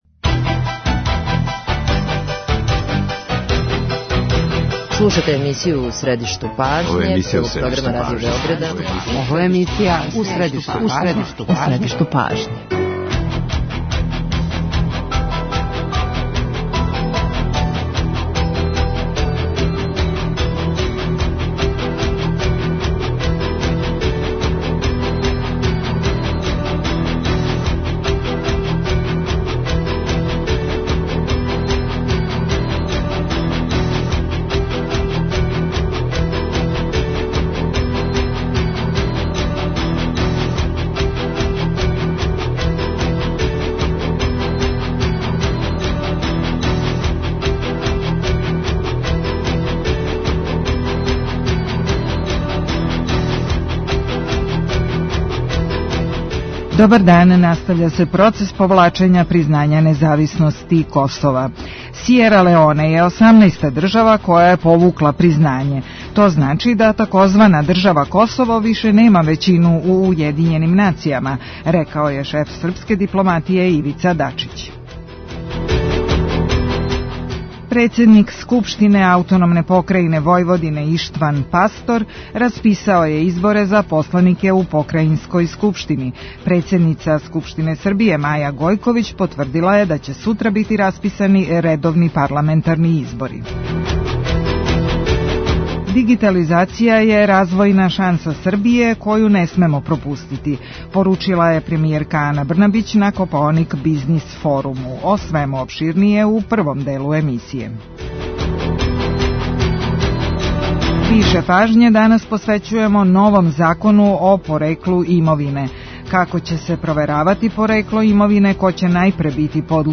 Гост емисије је Радомир Илић, државни секретар у Министарству правде.